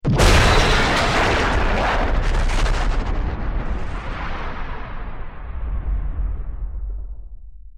otherdestroyed1.wav